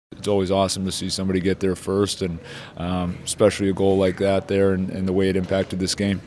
Coach Dan Muse says Hallander’s goal was a special moment for him and for the Pens.